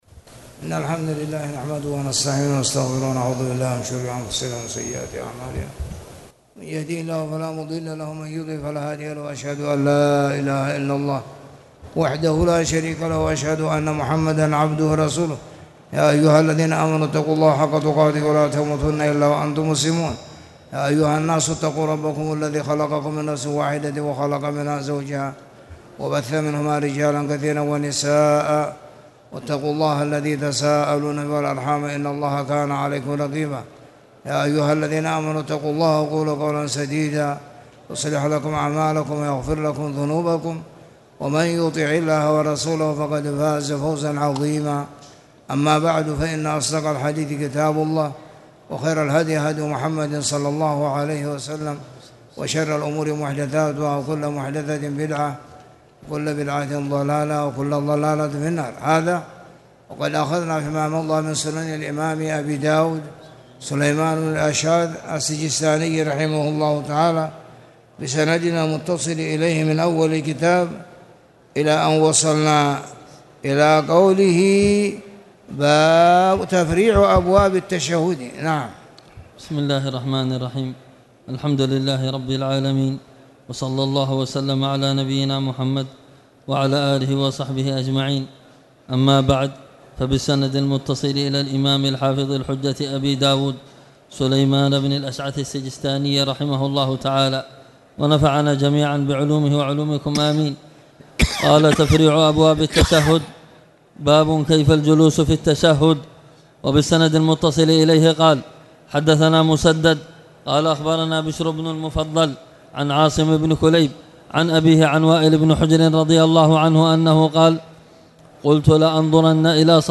تاريخ النشر ١٤ ربيع الأول ١٤٣٨ هـ المكان: المسجد الحرام الشيخ